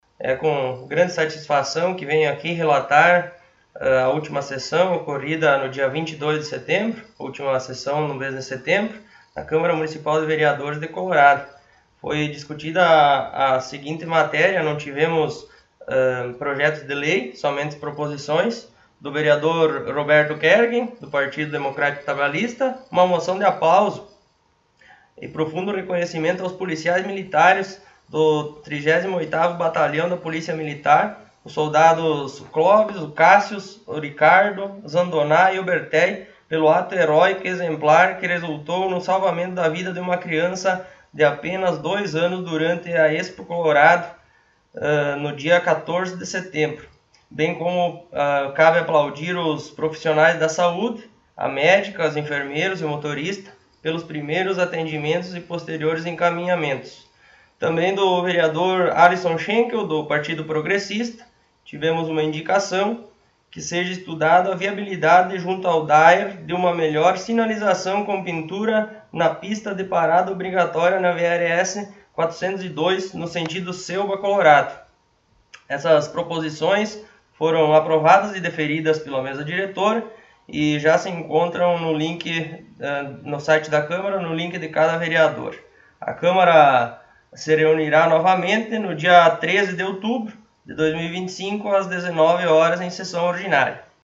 Presidente do Poder Legislativo, Taciano Paloschi, concedeu entrevista